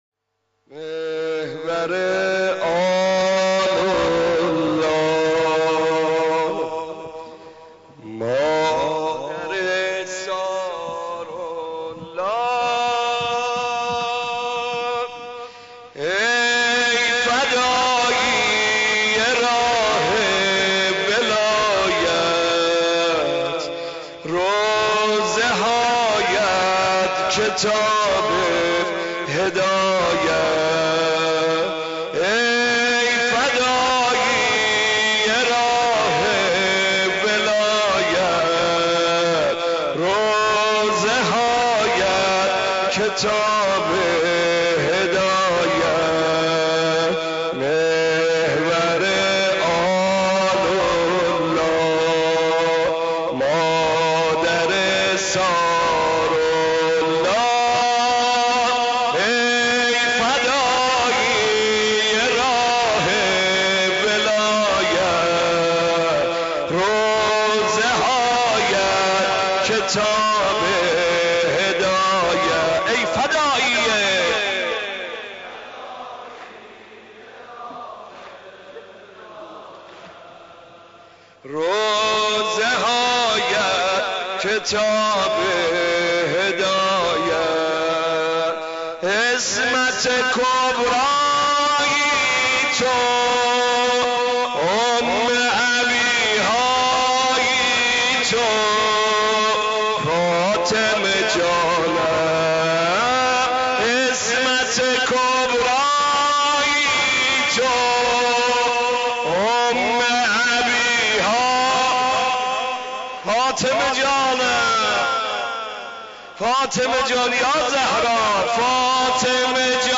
مداحی زیبای